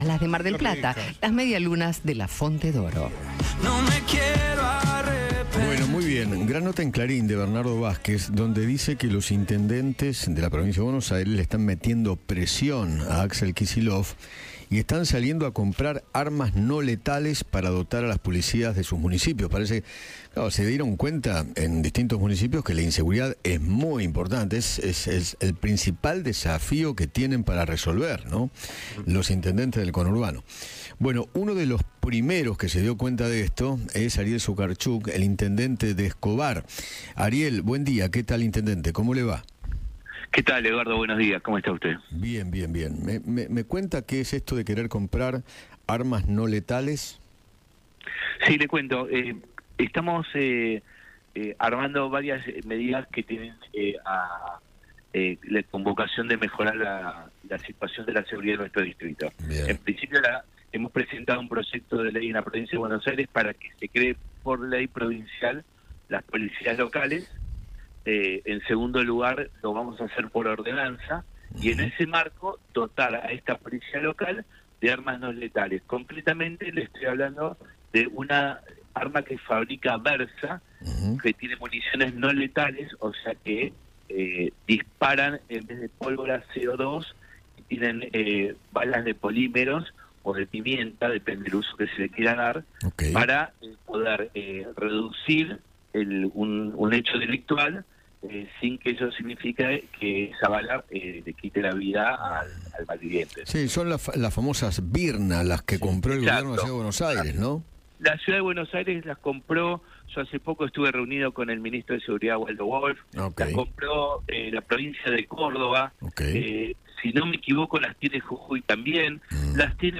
El intendente de Escobar, Ariel Sujarchuk, conversó con Eduardo Feinmann sobre las críticas que recibió por la adquisición de nuevas armas para equipar a su policía local.